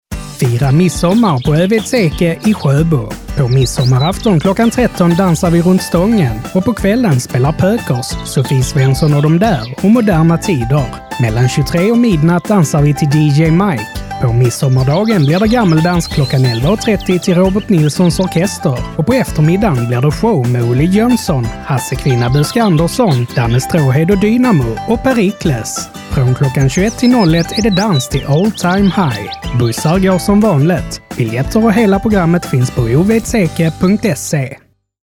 Årets Radioreklam 2025! – Midsommardagar 20 – 21 juni 2025 i Öveds Eke.